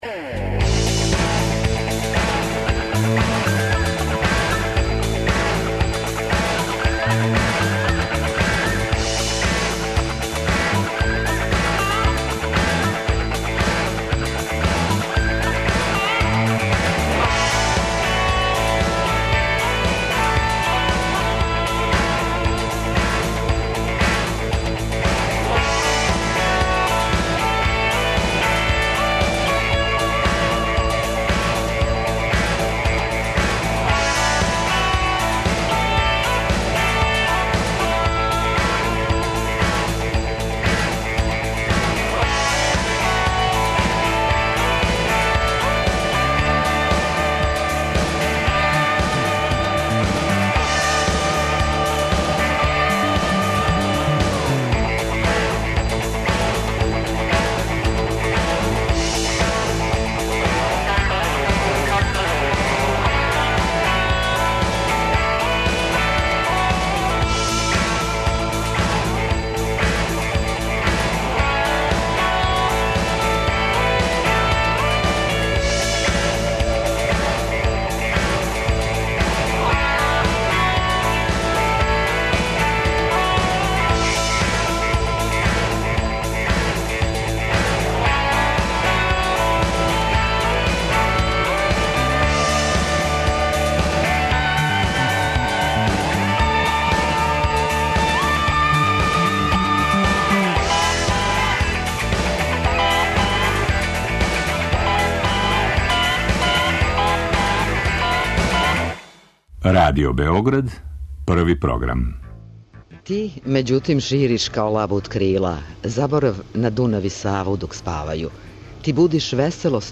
Са Калемегдана о Калемегдану. Екипа Радио Београда 1 је у срцу Калемегдана на тениским теренима, са друге стране кошаркашких, у сенци платана.